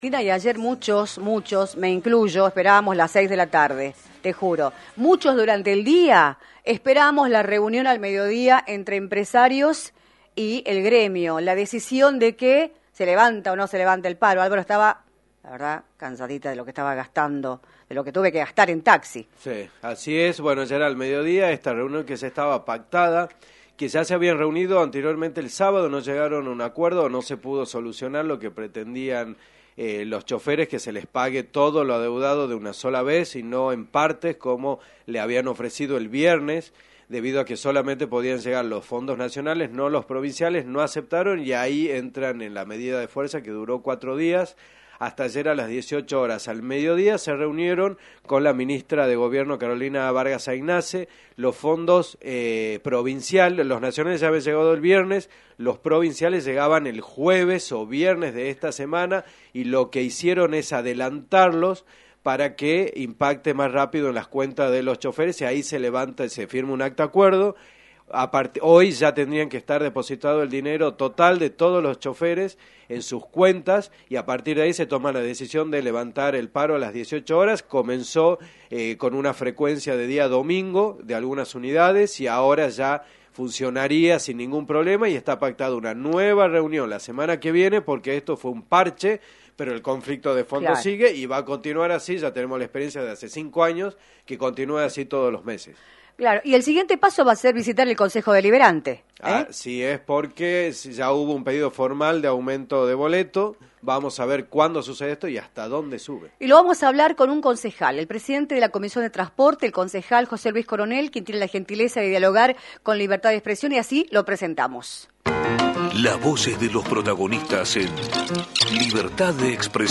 José Luís Coronel, Concejal de San Miguel de Tucumán y Presidente de la Comisión de Transporte, informó en “Libertad de Expresión”, por la 106.9, la situación actual del conflicto que llevan adelante los trabajadores y representantes de UTA con los empresarios de AETAT, luego de que, después de 4 días, se levantara el paro de colectivos, al mismo tiempo que el sector privado presentó un pedido de aumento en el boleto.